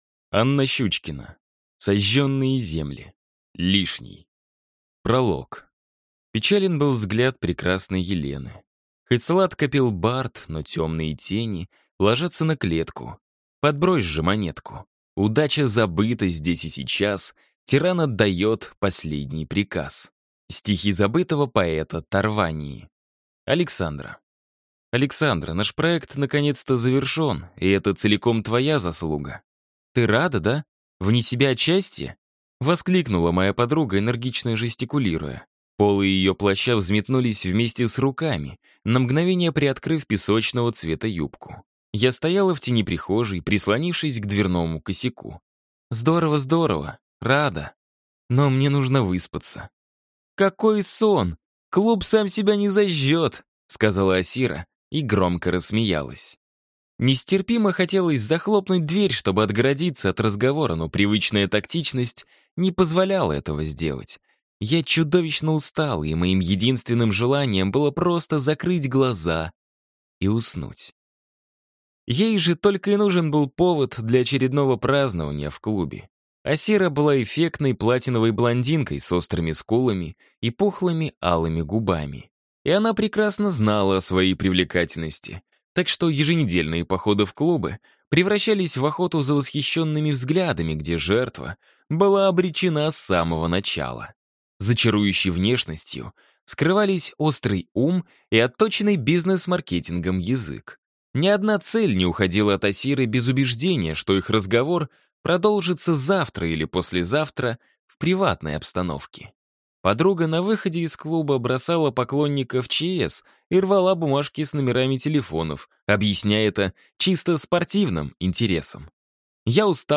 Аудиокнига Сожженные земли. Лишний | Библиотека аудиокниг